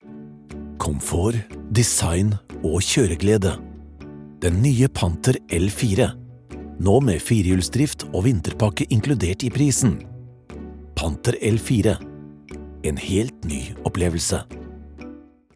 Tief, Vielseitig, Zuverlässig, Erwachsene, Warm
Kommerziell